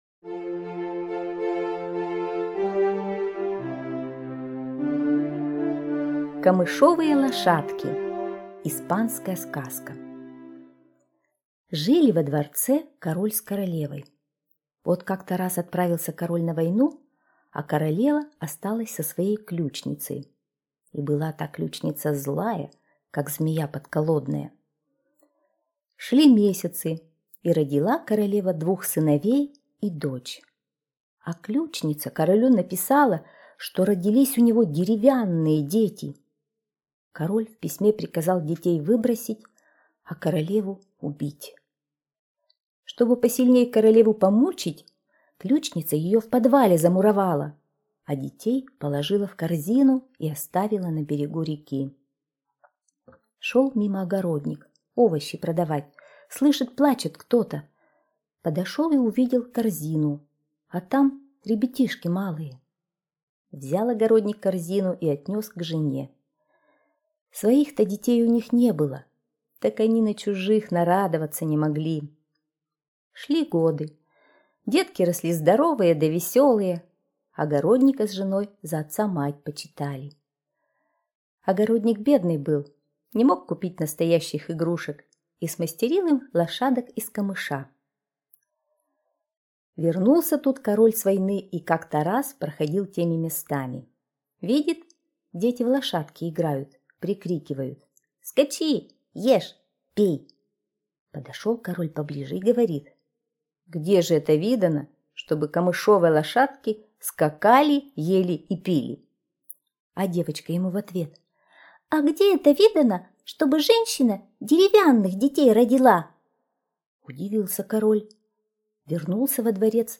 Камышовые лошадки — испанская аудиосказка.